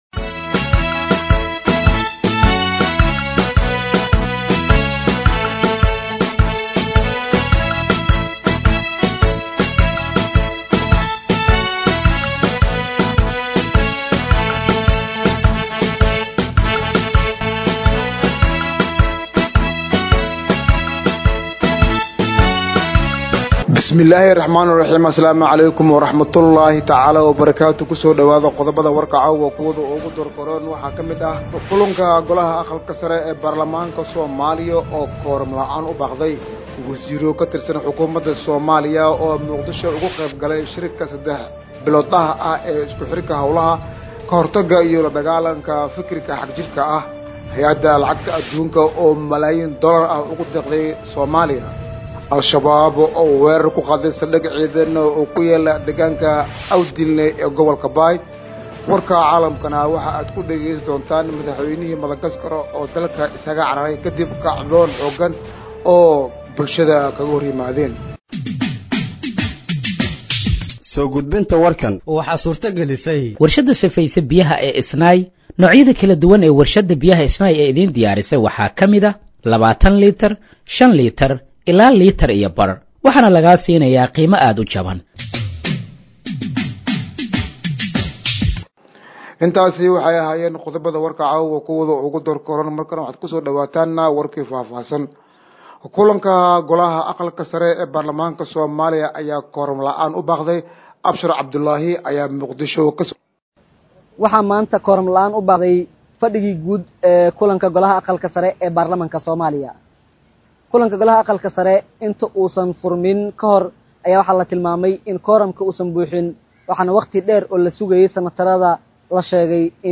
Dhageeyso Warka Habeenimo ee Radiojowhar 13/10/2025